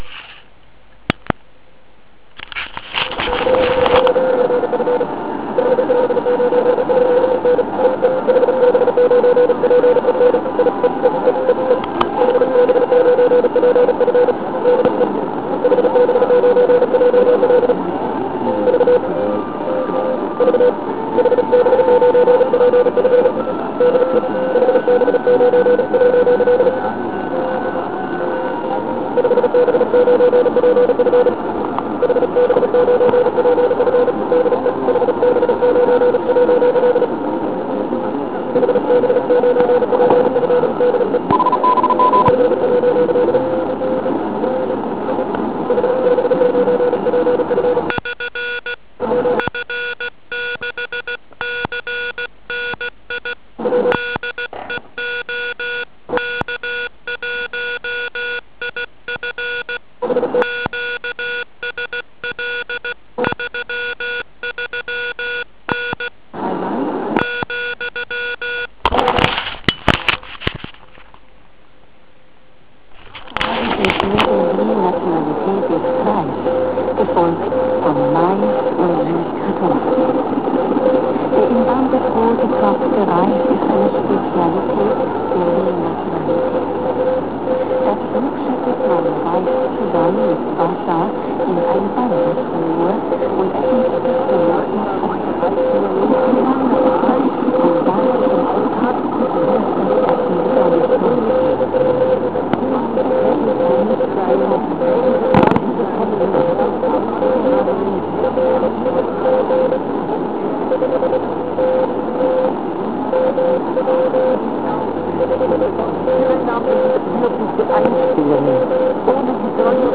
Kolem 20. hodiny u další kávičky dělám svoji 19. zem s 500 mW.